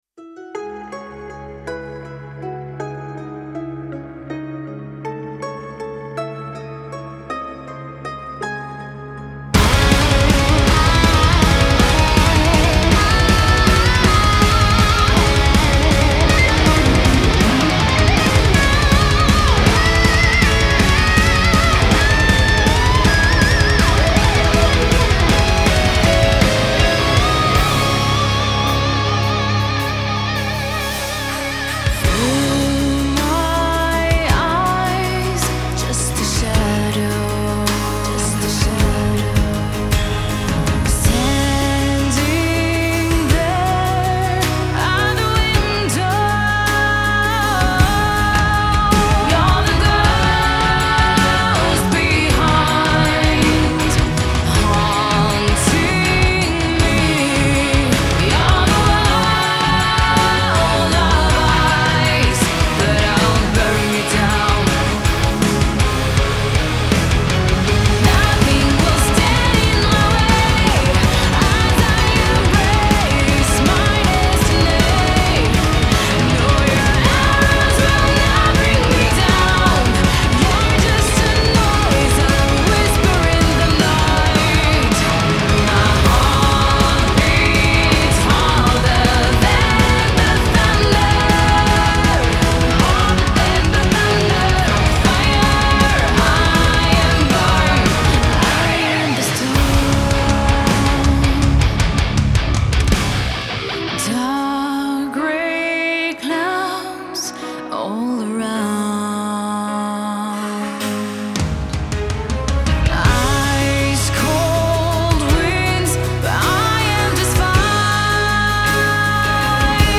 Genre : Metal